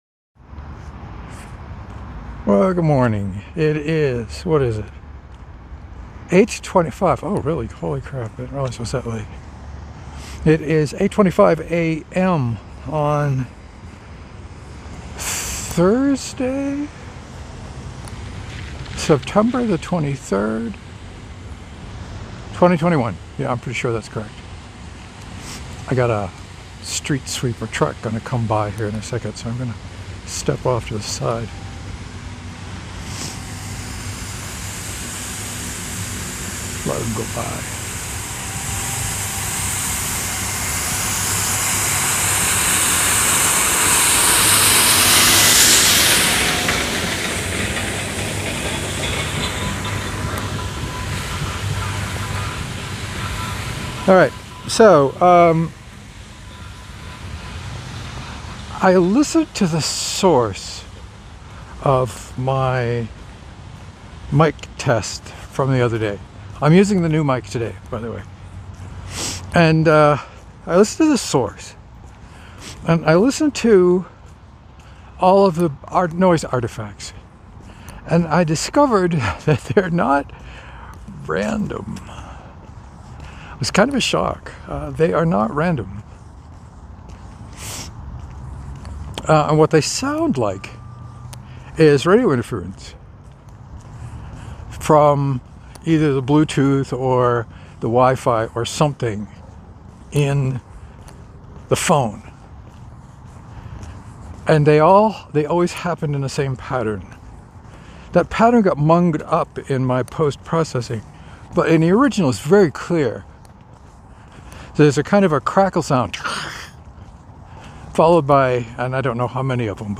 Podcast was wonderfully clear and easy to hear today.